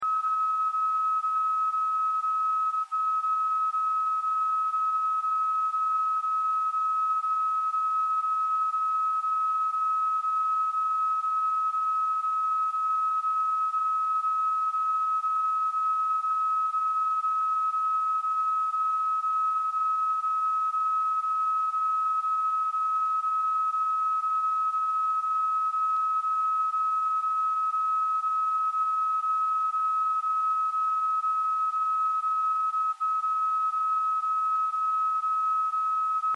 Station HBG is a time/frequency station located in Prangins Switzerland on 75.0 kHz with 25 kw power. This station was logged on October 2-5, 2009.
The transmitter is ASK modulated and the tone heard in the recording is of the 1,500 Hz BFO (receiver was set to SSB).
HBG_75kHz_36sec.mp3